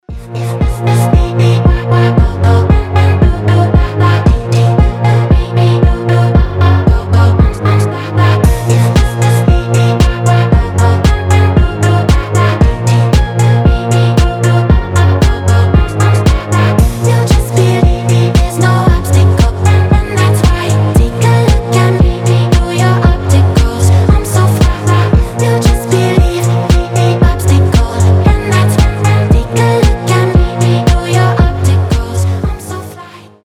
• Качество: 320, Stereo
deep house
атмосферные